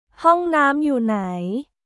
ホンナーム・ユー・ナイ？